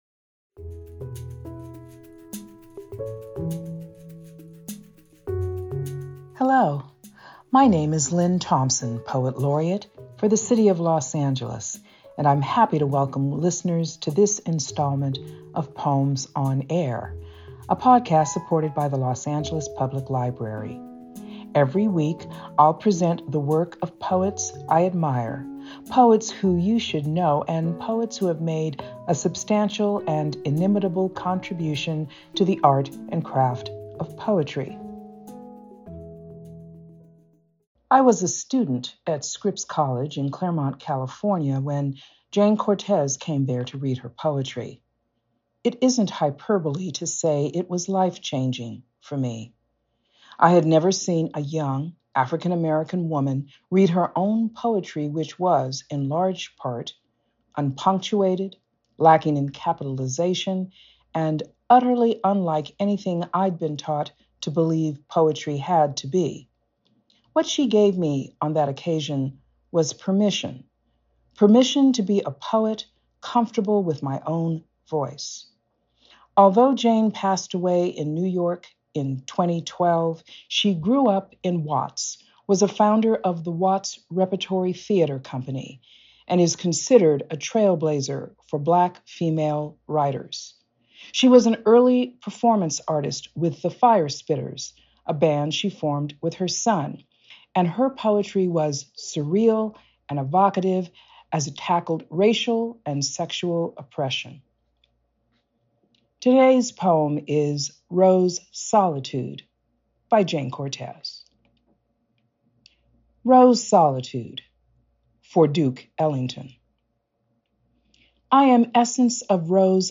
Los Angeles Poet Laureate Lynne Thompson reads Jayne Cortez’s “Rose Solitude”.